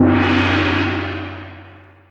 soundblocks_gong.ogg